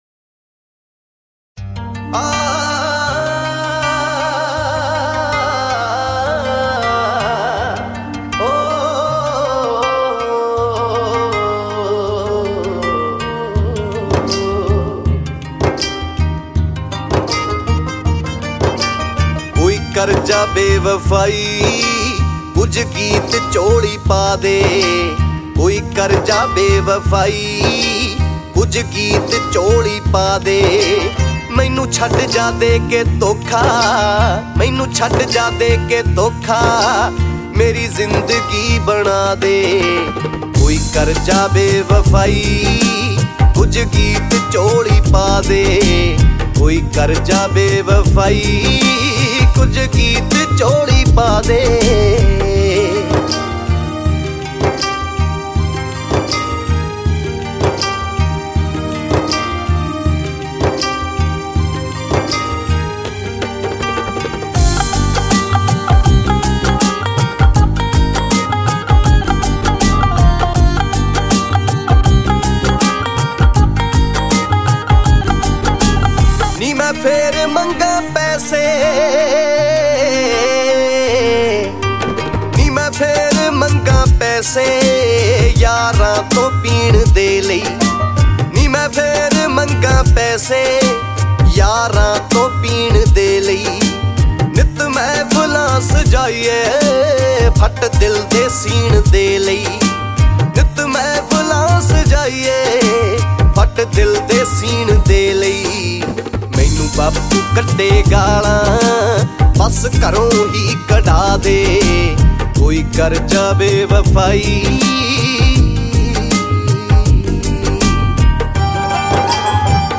old Punjabi song